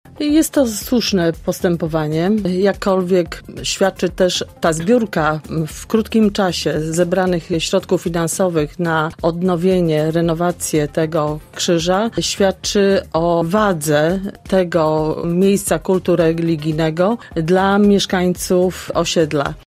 – To dobry pomysł – oceniła radna Prawa i Sprawiedliwości:
Bożena Ronowicz była gościem Rozmowy po 9.